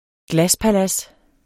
Udtale [ ˈglaspaˌlas ]